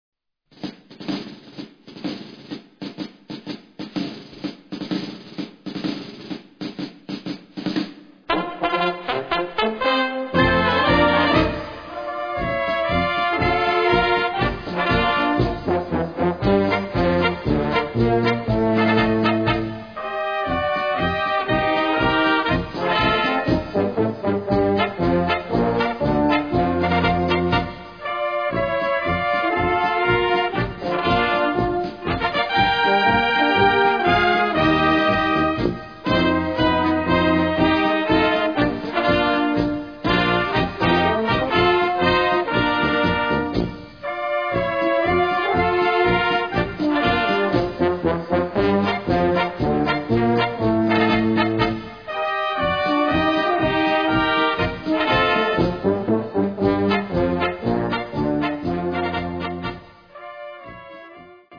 Gattung: Böhmischer Marsch
Besetzung: Blasorchester